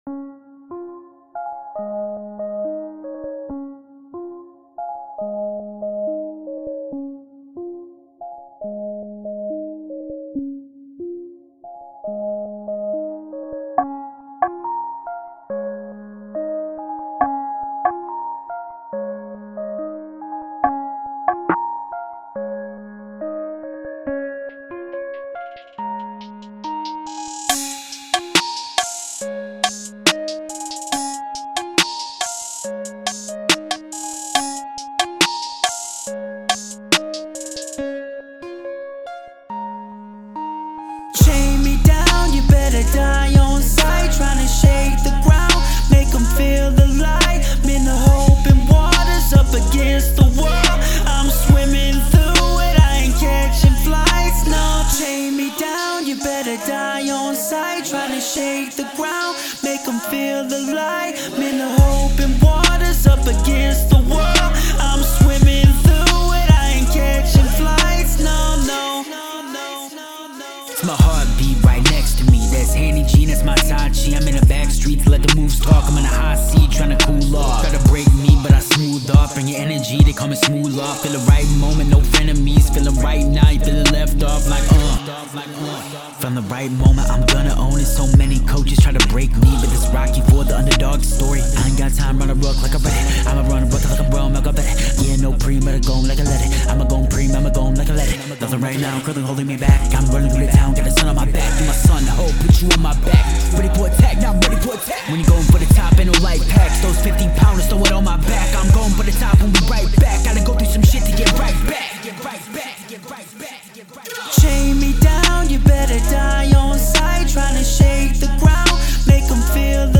Rap
melodic undertones and 808's